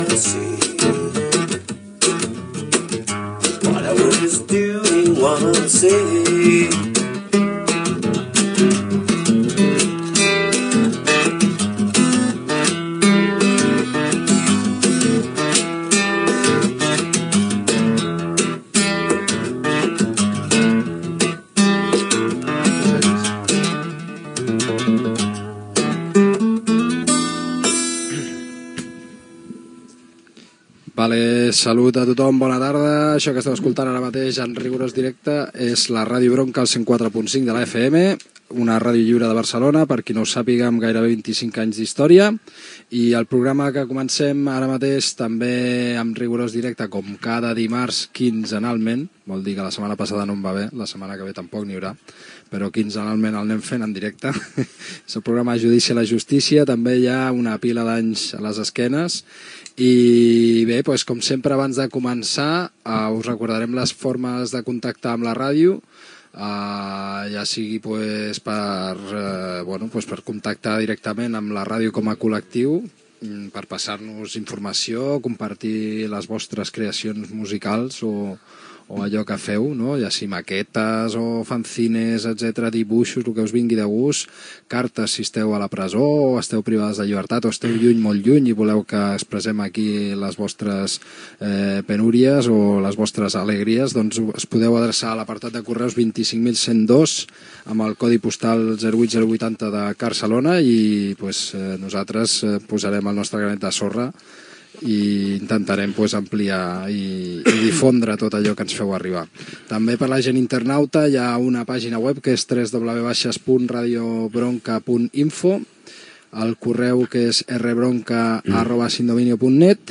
d0bd0b7215c2164f0000cd0dad5b94db0d720286.mp3 Títol Ràdio Bronka Emissora Ràdio Bronka Titularitat Tercer sector Tercer sector Lliure Nom programa Judici a la justícia Descripció Tema musical, identificació de la ràdio, inici de l'espai, formes de contactar amb l'emissora, la Coordinadora de Ràdios Lliures de Catalunya, informació de la denúncia de les entitats de gestió de drets d'autor a Radio Pica.